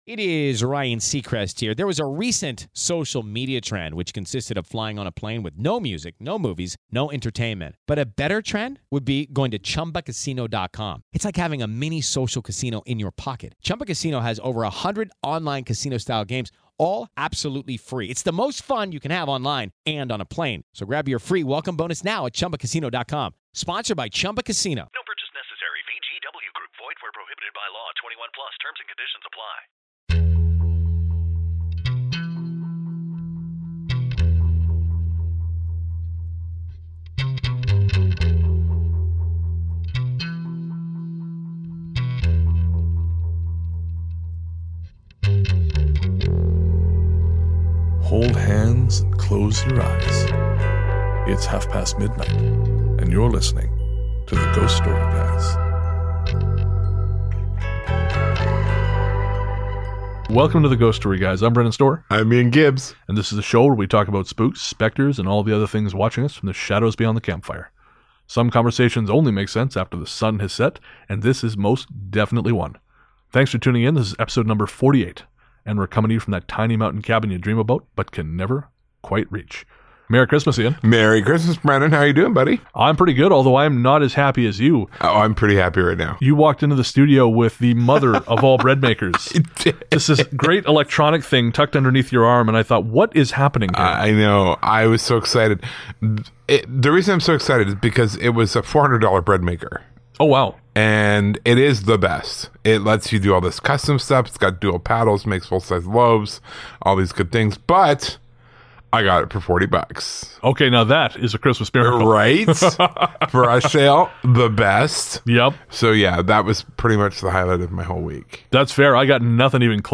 Whether you call it Christmas, Yule, Wintermas, or just another damn day, this used to be the season for scares; families would gather round the fire and run out the long, cold hours till dark by telling one another spooky stories. On this episode we carry that tradition forward by reading you four short stories by none other than HP Lovecraft.